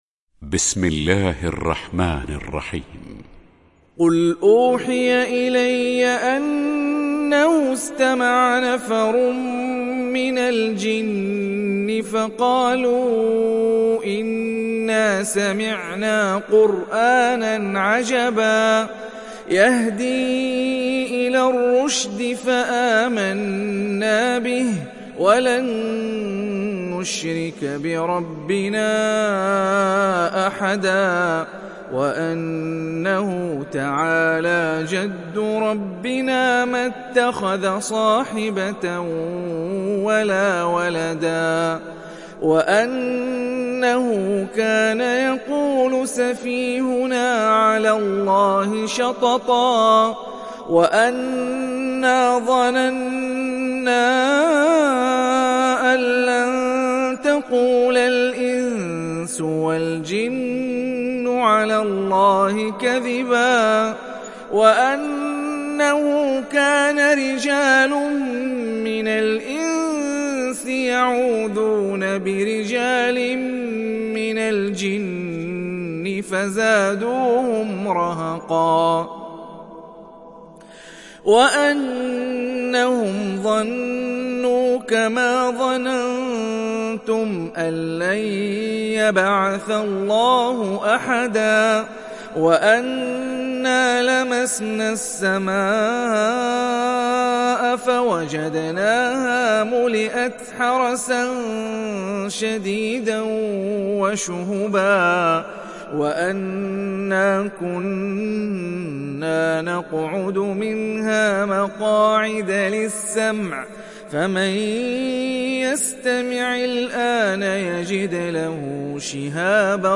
সূরা আল-জিন্ন ডাউনলোড mp3 Hani Rifai উপন্যাস Hafs থেকে Asim, ডাউনলোড করুন এবং কুরআন শুনুন mp3 সম্পূর্ণ সরাসরি লিঙ্ক